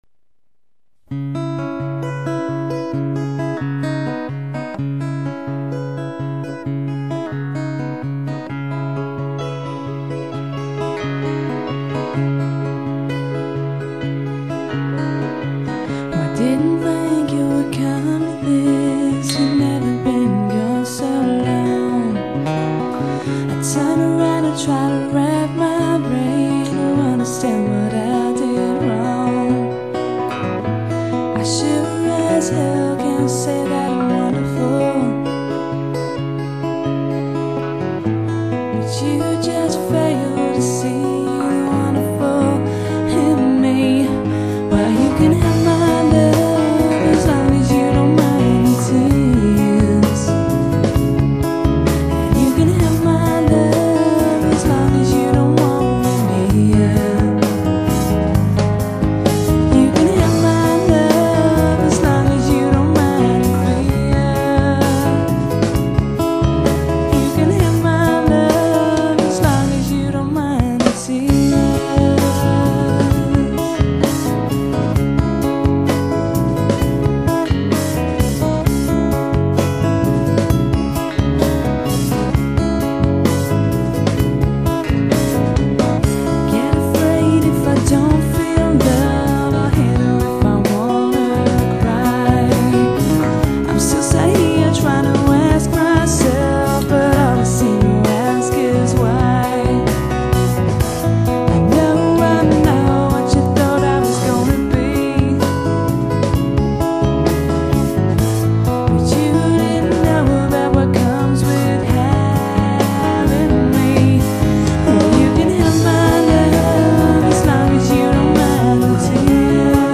LISTEN TO THIS DEMO
keyboard player
piano and keyboards